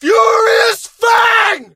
fang_hurt_vo_02.ogg